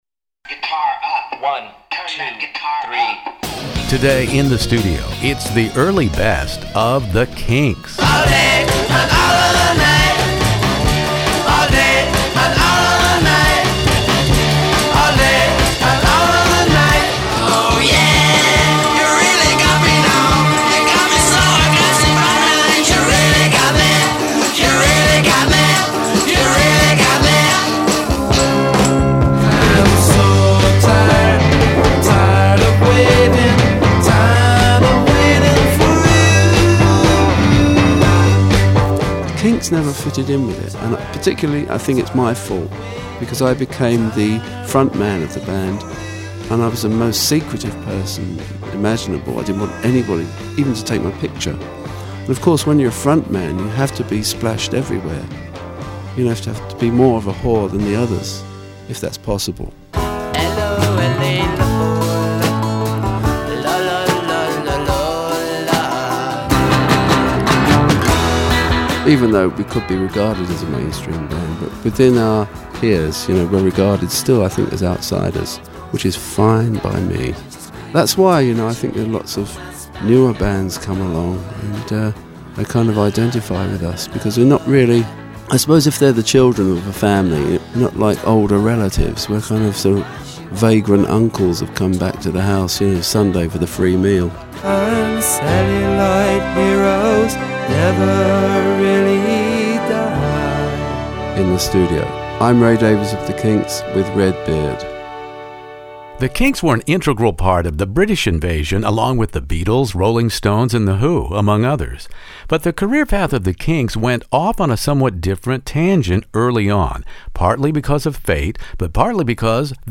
Sir Ray joins me here In the Studio for a droll, witty, incisive look back at the #1 “Lola”,”Apeman”, “Top of the Pops”, and more.